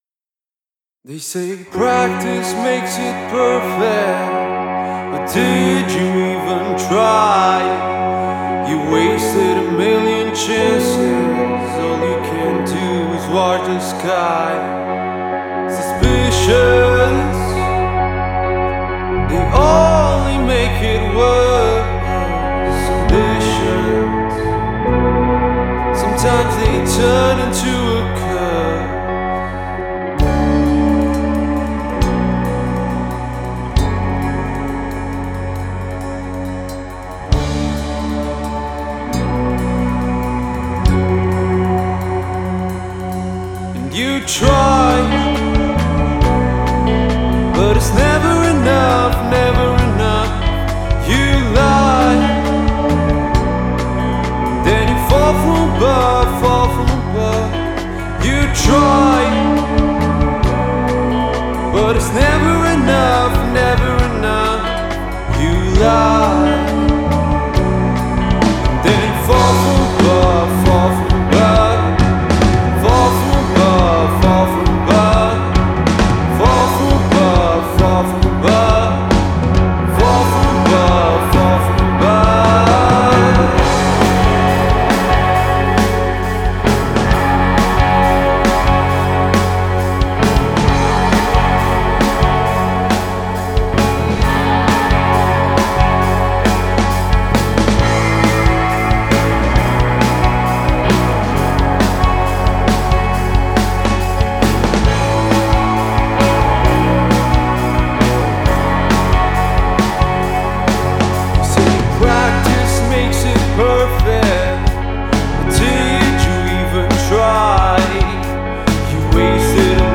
duo de rock alternativ cu influenţe de post-rock
voce, chitară